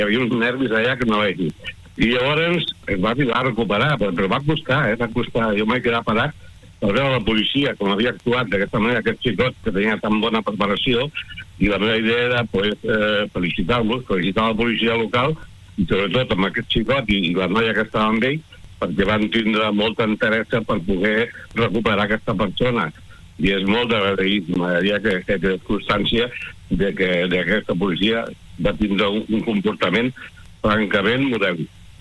En una entrevista a l’FM i + ha relatat com van anar els fets, ahir pels volts de les set de la tarda.